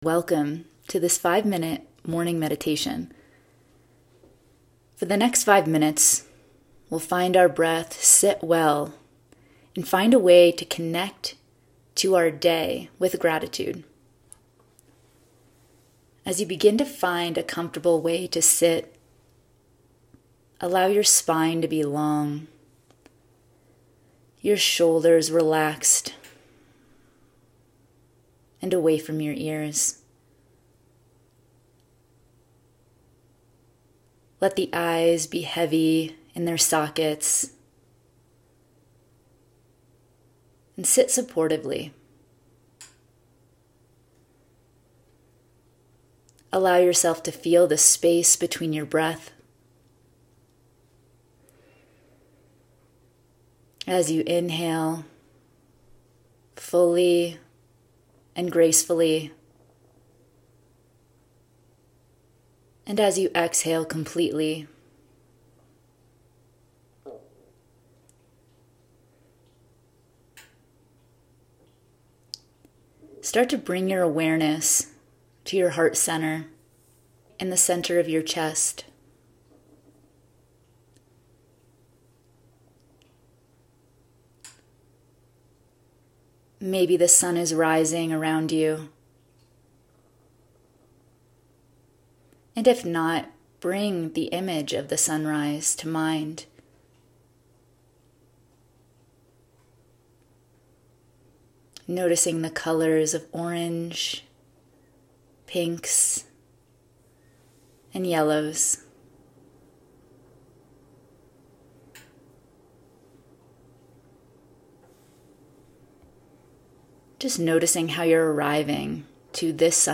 Experience serenity with 'Rising in Gratitude,' a 5-minute morning meditation to start your day with mindful breathing, heart-centered gratitude, and sunrise imagery.